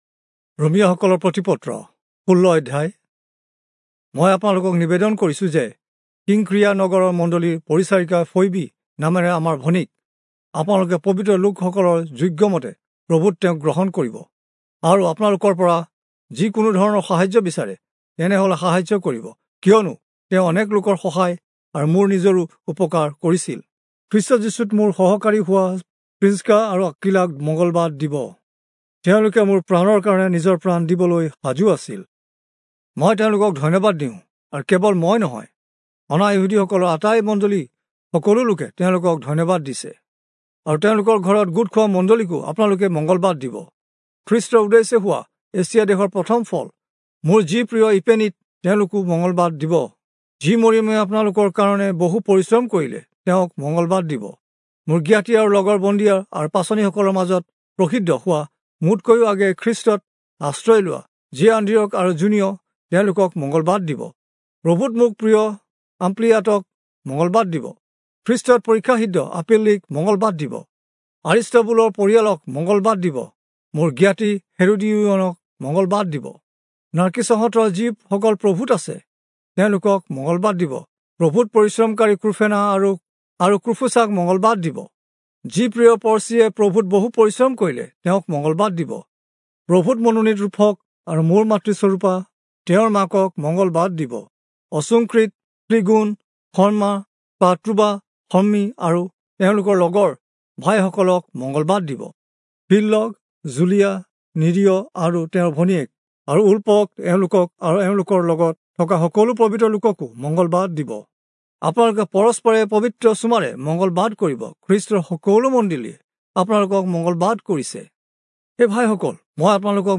Assamese Audio Bible - Romans 8 in Irvmr bible version